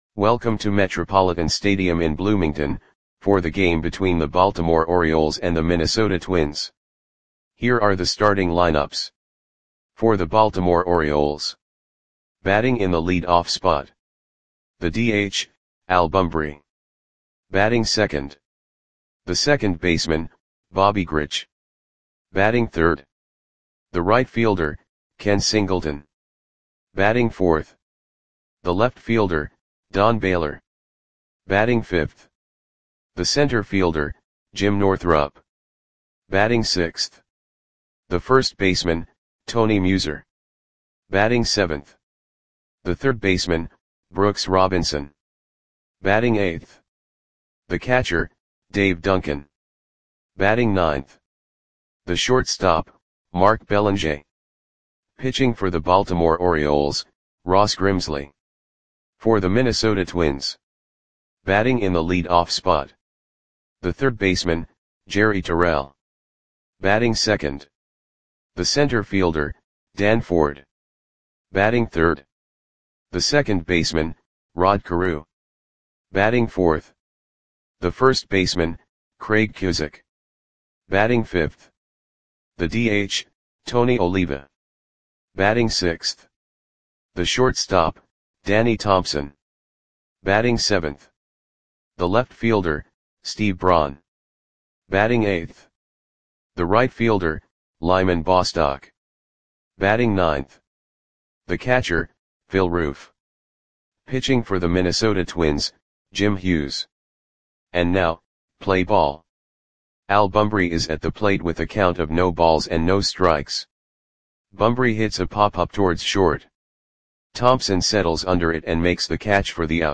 Audio Play-by-Play for Minnesota Twins on August 20, 1975
Click the button below to listen to the audio play-by-play.